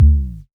Urban Sub 02.wav